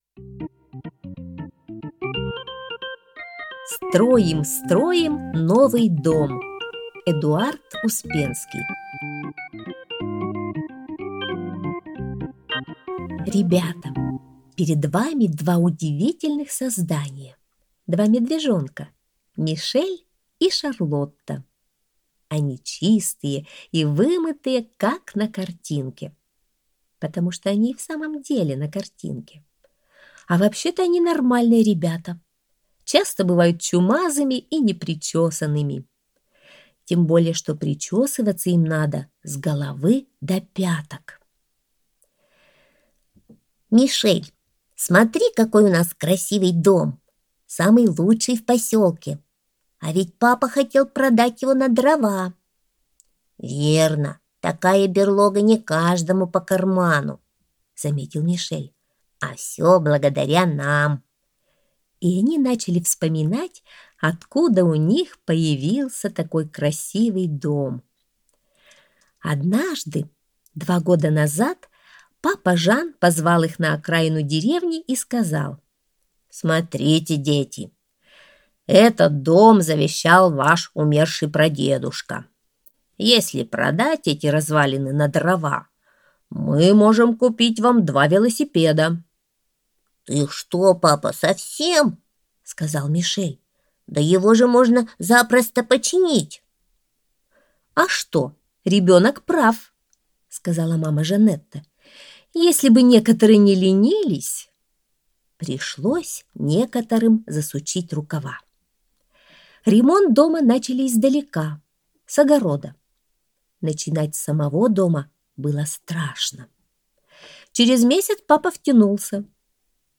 Аудиосказка «Строим, строим новый дом»